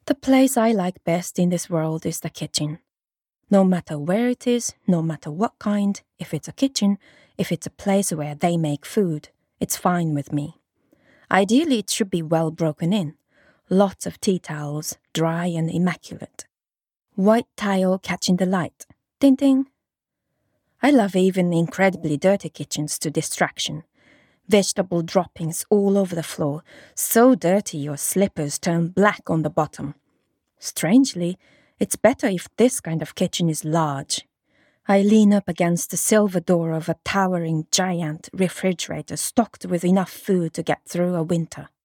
Japanese, Female, Home Studio, Teens-30s
Home Studio Read